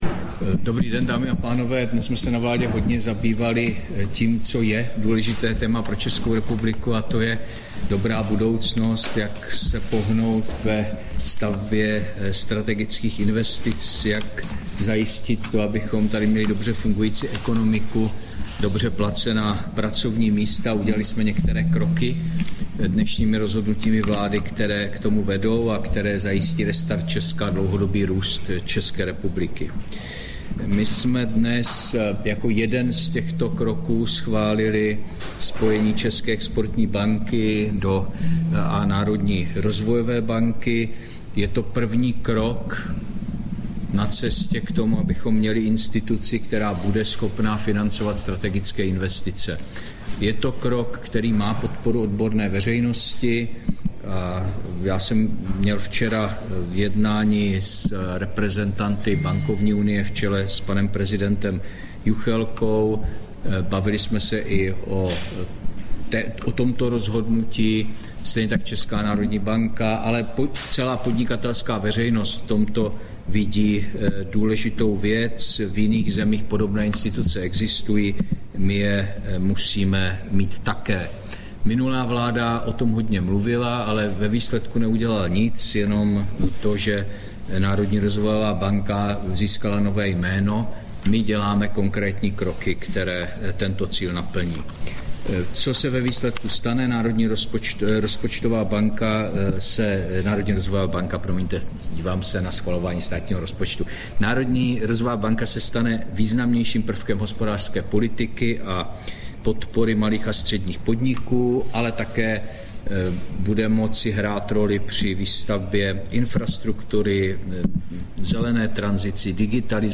Tisková konference po jednání vlády, 29. listopadu 2023